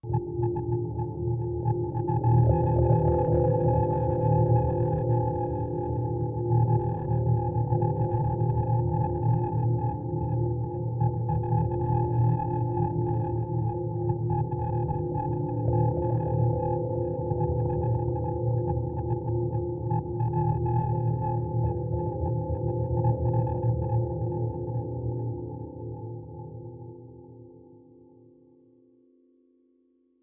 Buzzrite Medium Metallic Vibration Mystic